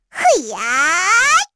Lilia-Vox_Casting4_kr.wav